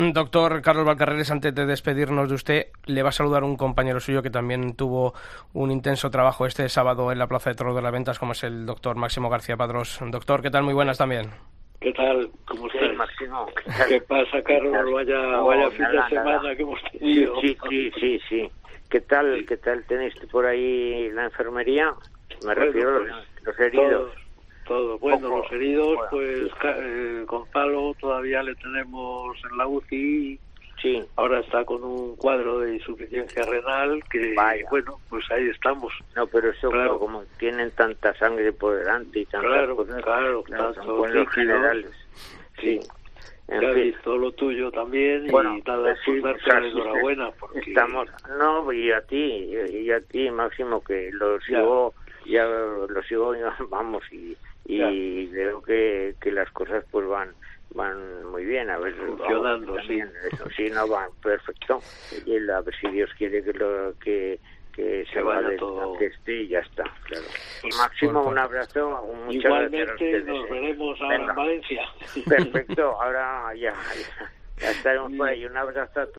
AUDIO: El Albero cruzó en antena a los dos doctores protagonistas del último fin de semana.